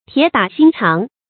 鐵打心腸 注音： ㄊㄧㄝ ˇ ㄉㄚˇ ㄒㄧㄣ ㄔㄤˊ 讀音讀法： 意思解釋： 謂心腸像鐵鑄成的。